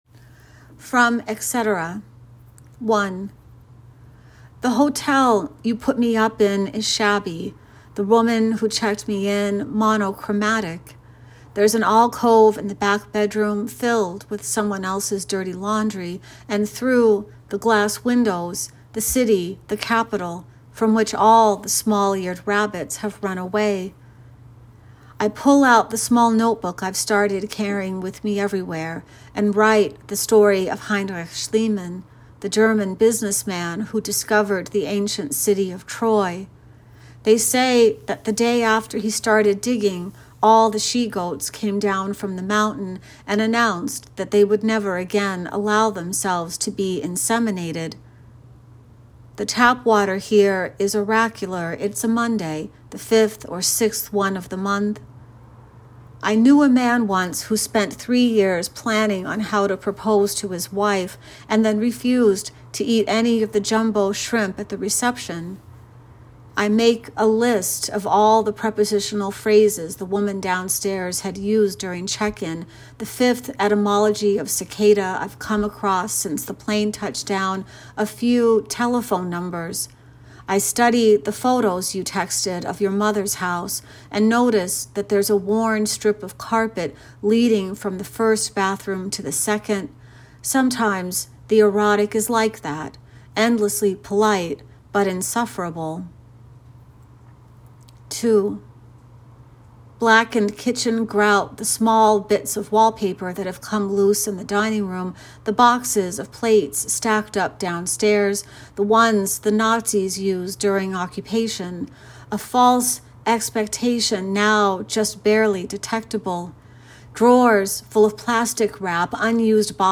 Authors with a speaker icon have graciously given us a recording of them reading their featured work.